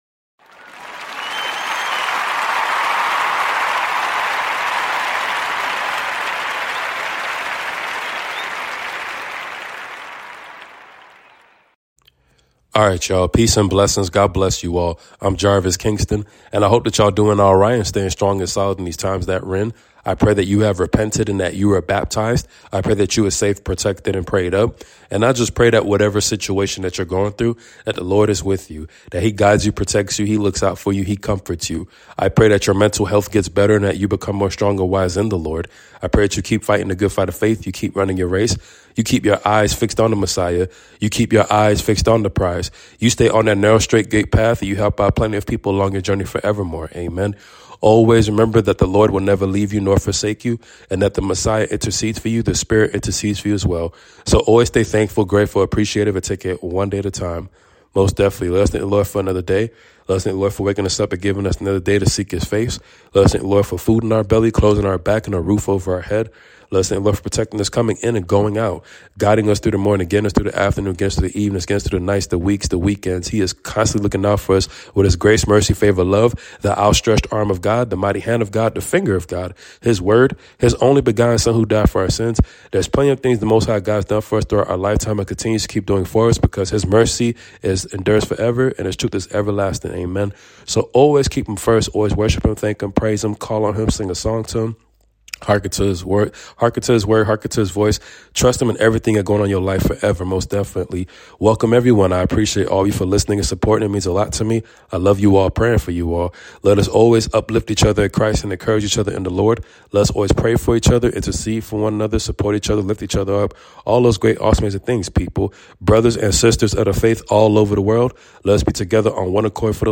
Your Nightly Prayer 🙏🏾 Psalm 27:1